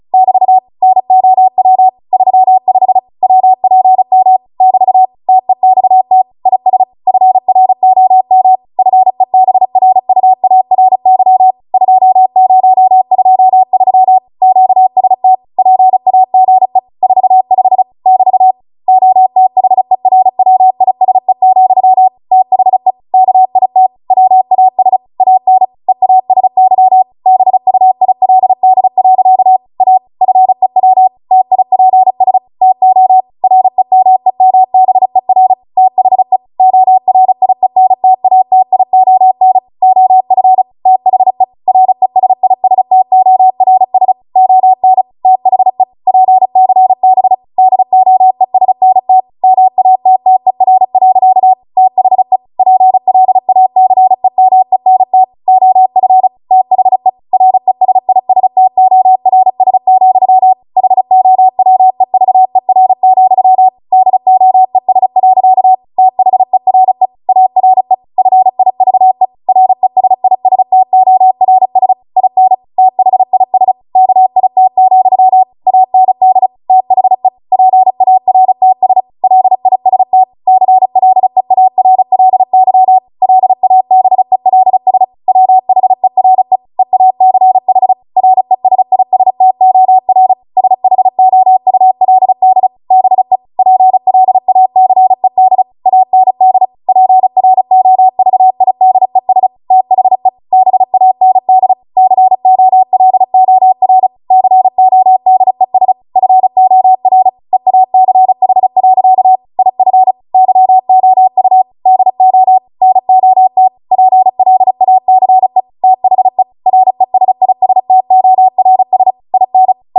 35 WPM Code Practice Archive Files
Listed here are archived 35 WPM W1AW code practice transmissions for the dates and speeds indicated.
You will hear these characters as regular Morse code prosigns or abbreviations.